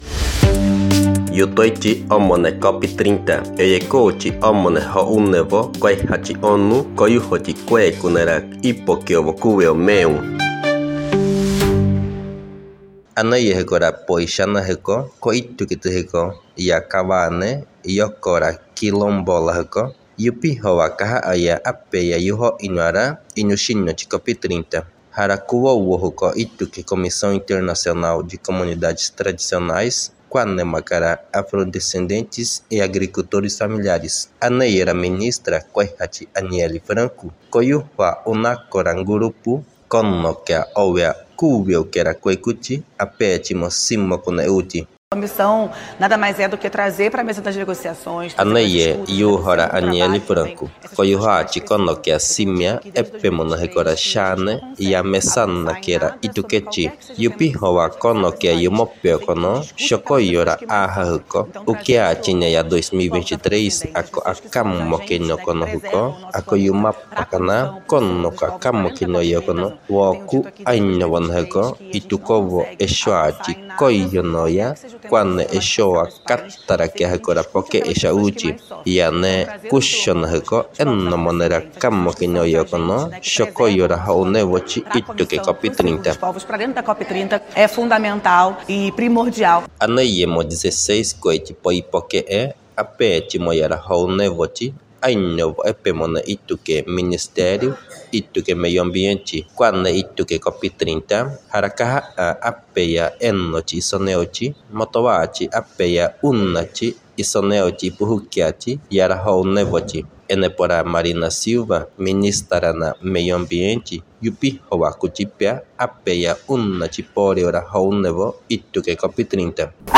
Boletins na língua indígena Terena são traduzidos e grvados em parceria com a Universidade Federal da Grande Dourados (UFGD), do estado do Mato Grosso do Sul.